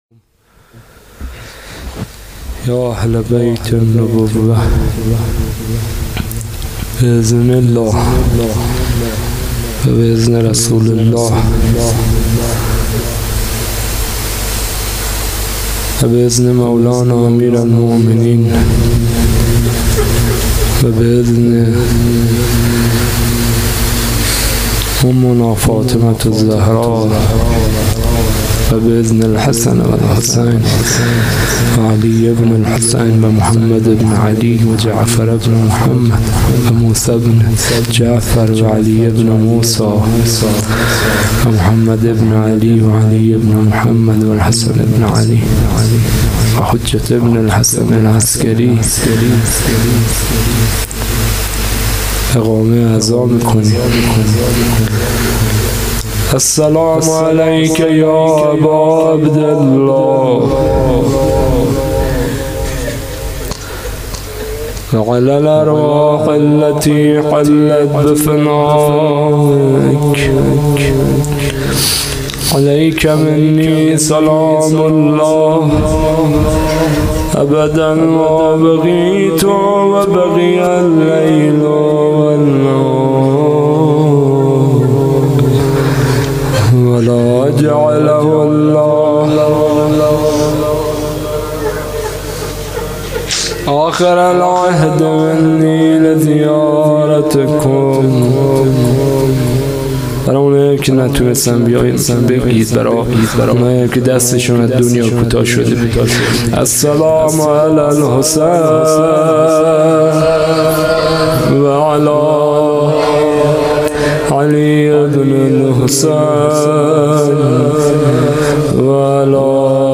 شب اول - روضه مسلم بن عقیل (ع)
هیئت مکتب الزهرا (س)